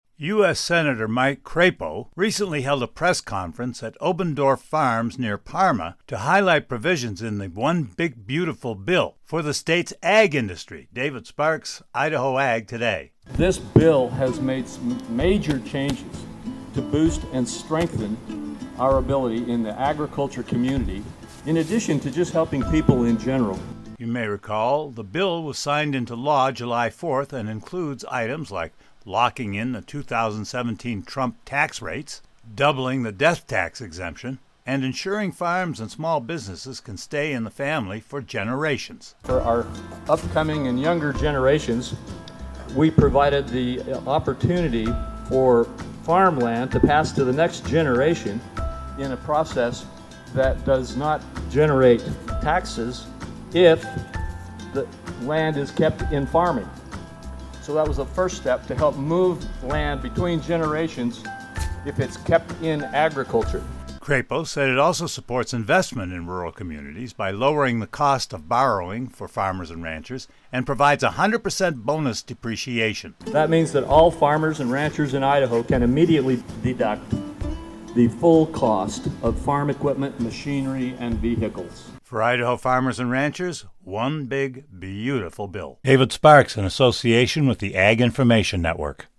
On August 20th the Senator held a press conference at Obendorf Farms near Parma to highlight key provisions in the bill for the state’s ag industry.